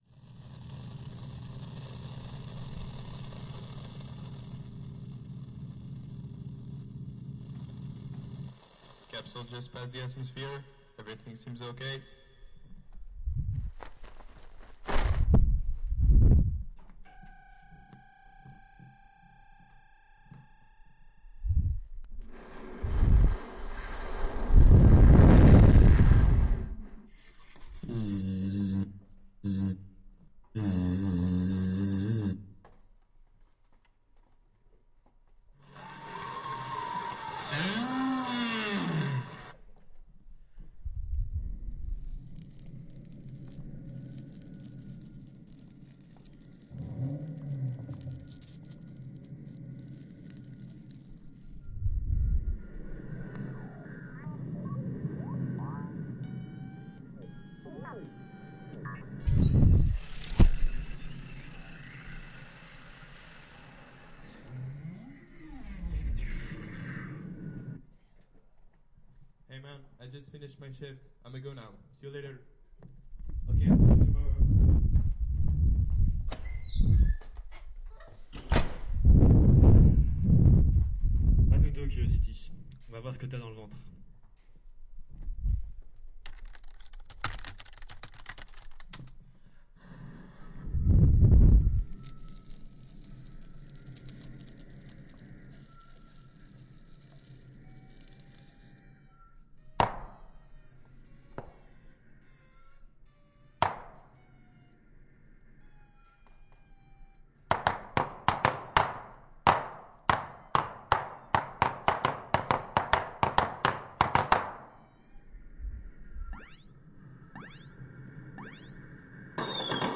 主题是将声音放在由他们发明并决定于2012年从美国国家航空航天局登陆火星上的好奇心空间任务的故事中。他们使用了不同的工具，如Foley，PD，freesound和嗓音效果。